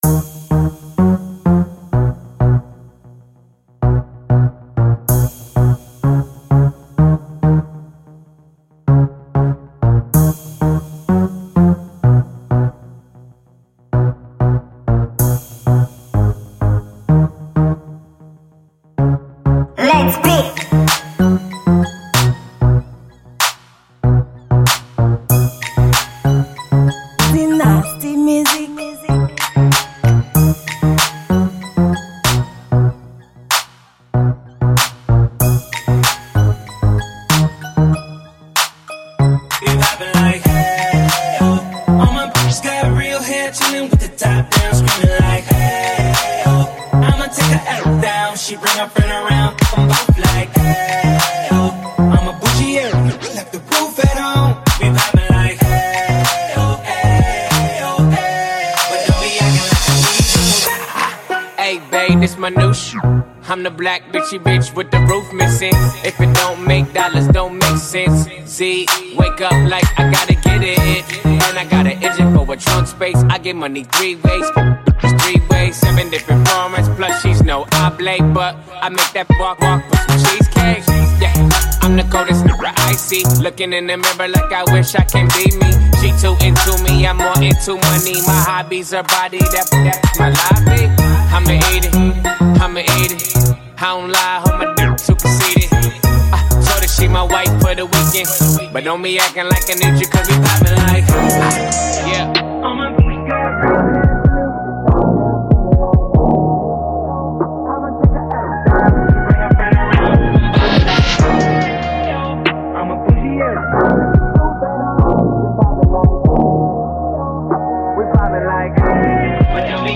Genre: Beat.